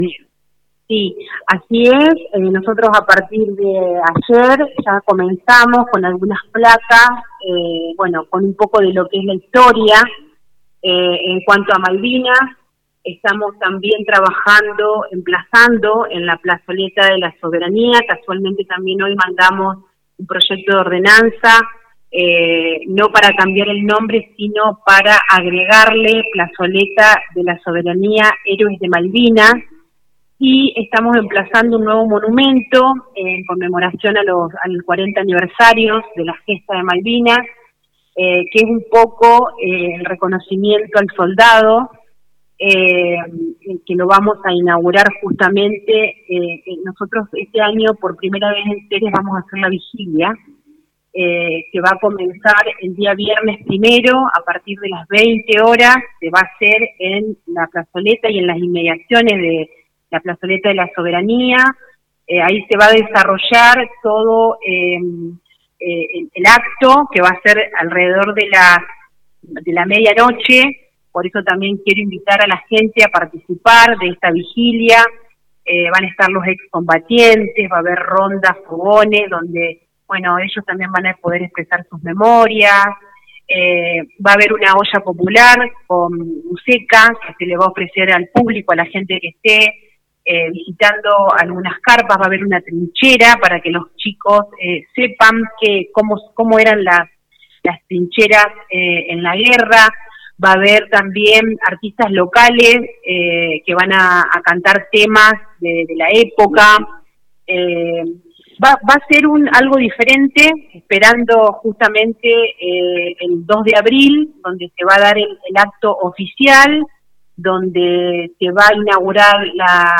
Escucha a la Intendente Alejandra Dupouy